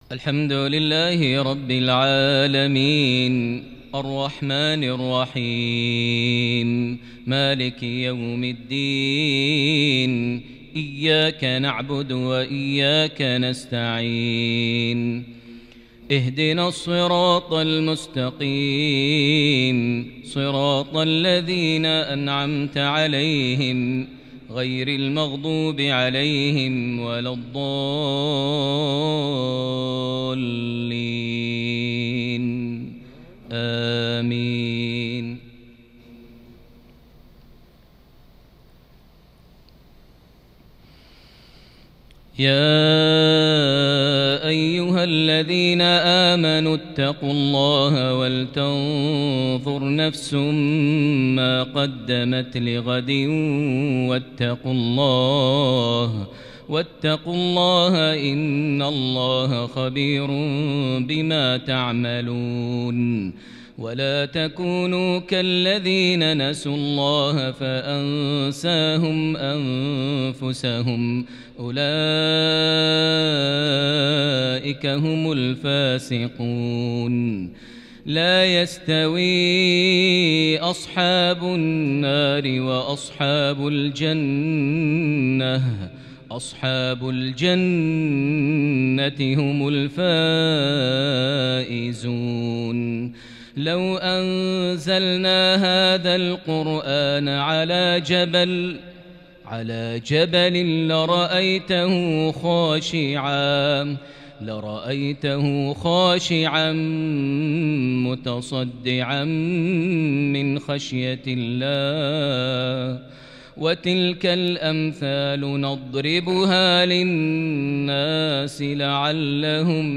تلاوة كردية لصلاة المغرب ١٩ شوال ١٤٤١هـ سورة الحشر ١٨-٢٤ > 1441 هـ > الفروض - تلاوات ماهر المعيقلي